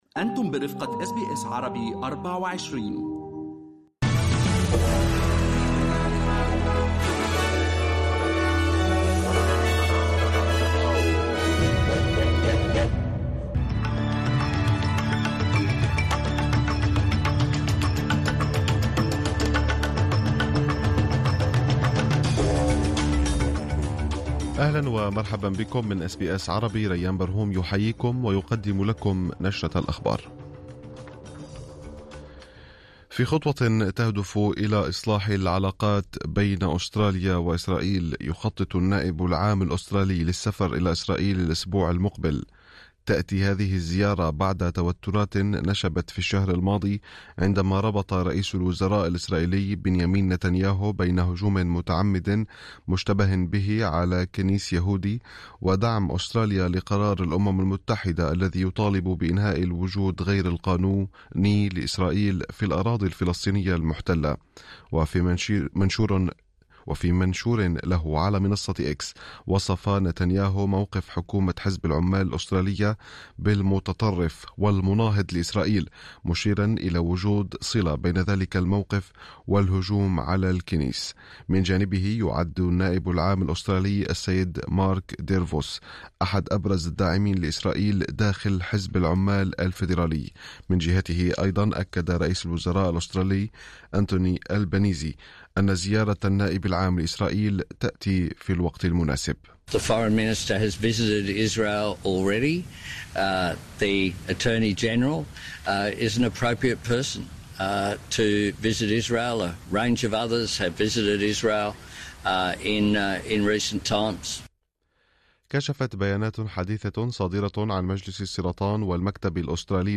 نشرة أخبار الظهيرة 07/01/2025